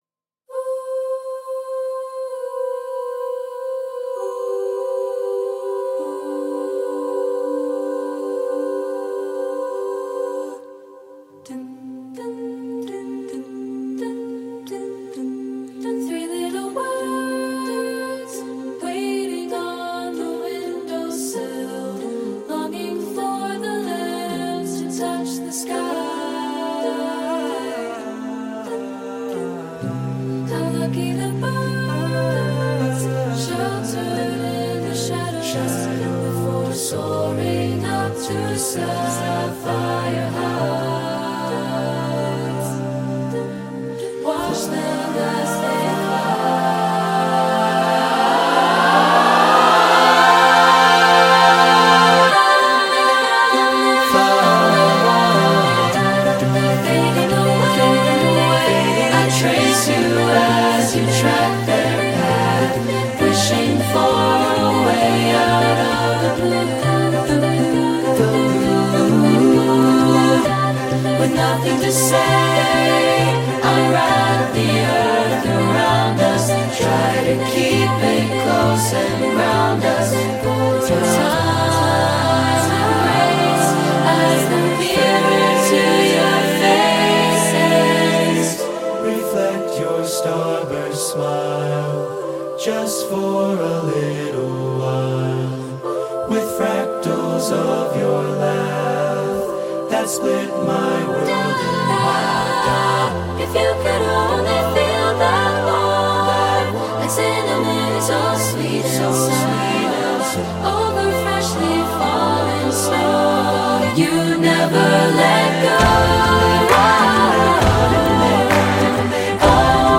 Voicing: SSAATTBB with Body Percussion